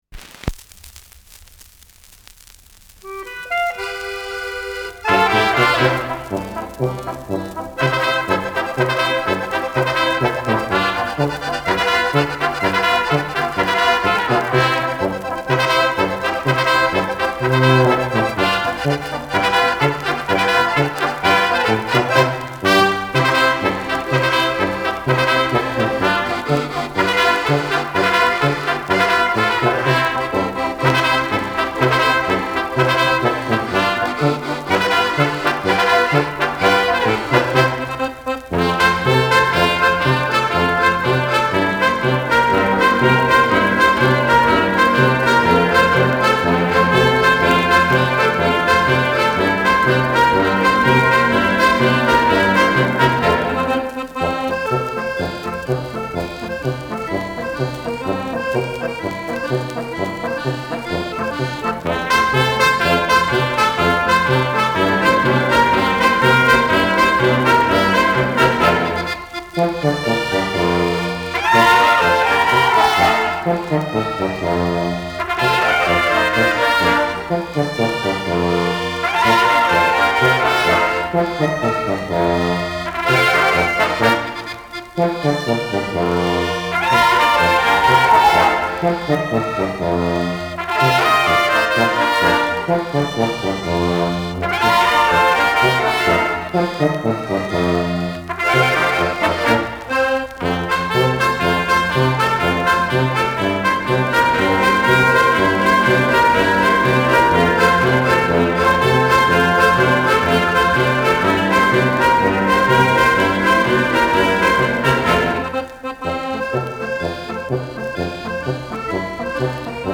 Schellackplatte
Tonrille: Kratzer Durchgehend Leicht